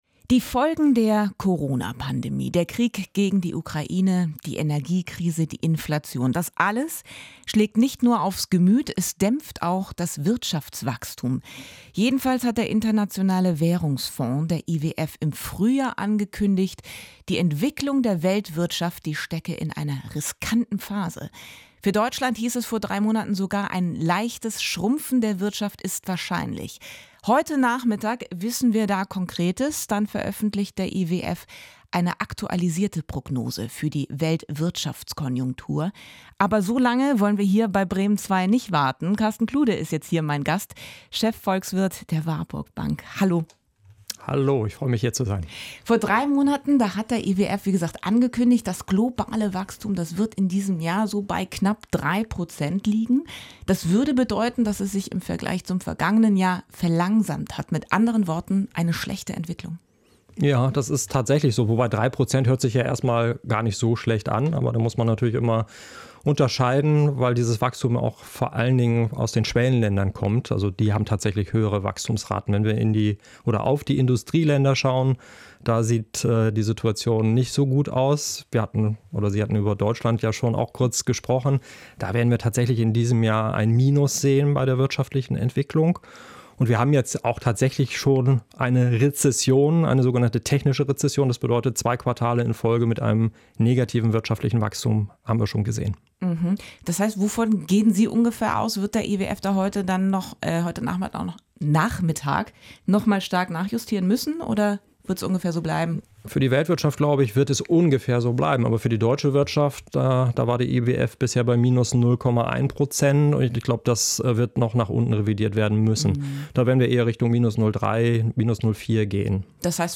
Downloads Zum Interview